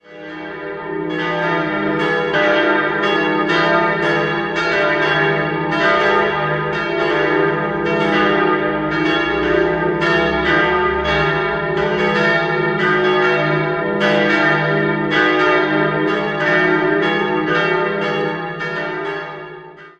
Juli 2024 wurde in St. Theresia der letzte Gottesdienst gefeiert und die Kirche profaniert. 3-stimmiges Gloria-Geläut: es'-f'-as' Die beiden kleineren Glocken wurden im Jahr 1900 von Johann Hahn in Landshut gegossen.
Regensburg_Theresia.mp3